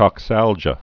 (kŏk-săljə)